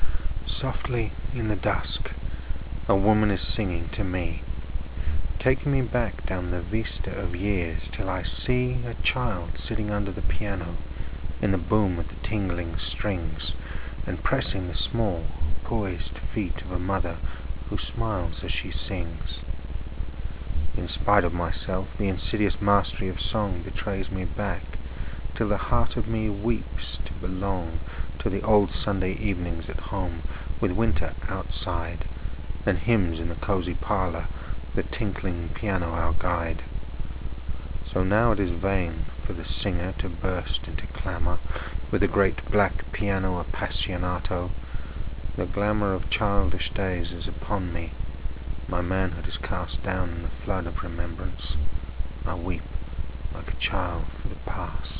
Click on the icon to the left to listen to a reading of the poem or read it aloud for yourself and consider what kind of effects are achieved.
lawrence_piano.wav